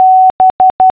International Morse Code
B · · ·